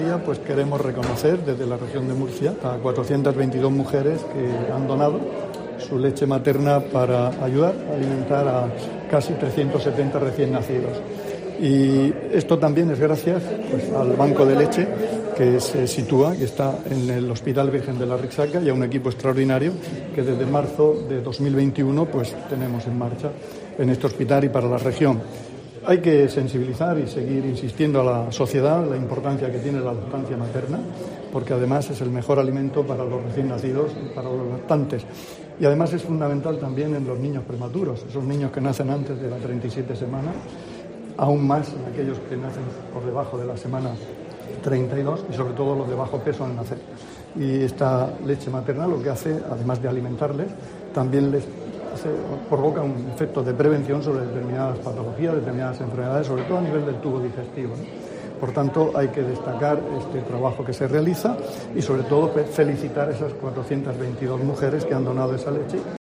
Juan José Pedreño, consejero de Salud